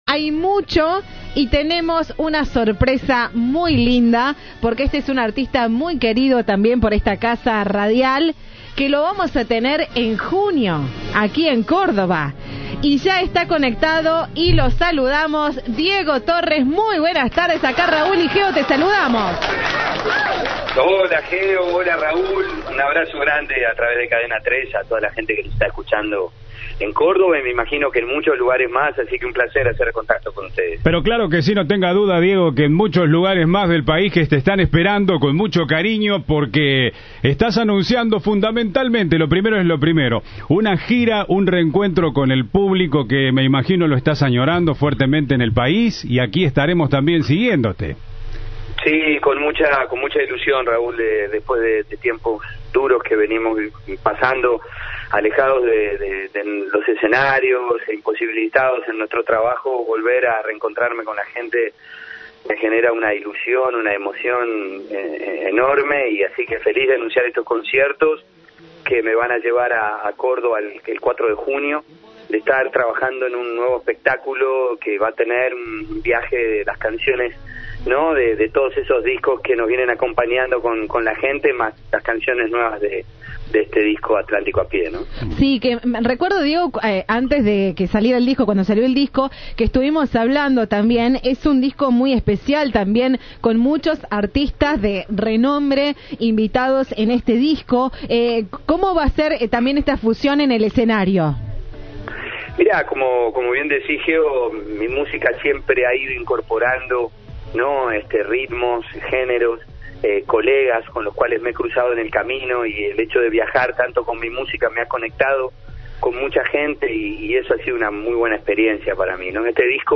El cantautor dialogó con Cadena 3 antes de su concierto del sábado 4 de junio en la Plaza de la Música. La gira incluye a las más importantes ciudades del país.